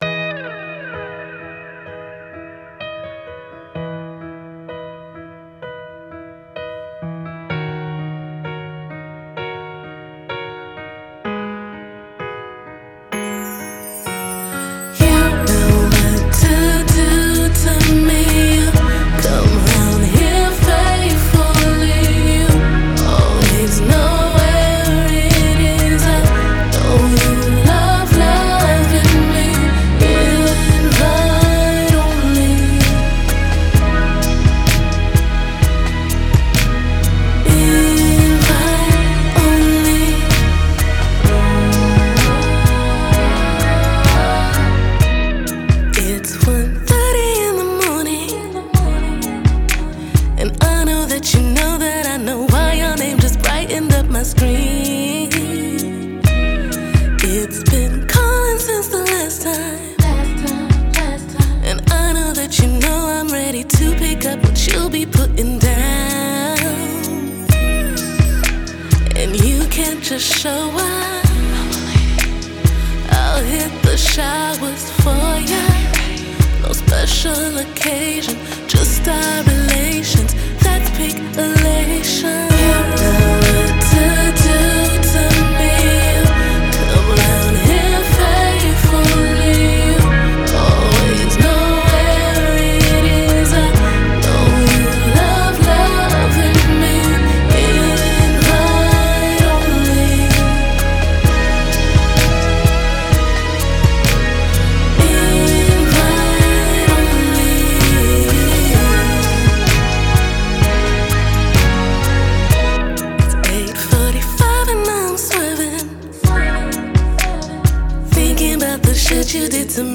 an rnb confessional that’s on that late 90s tip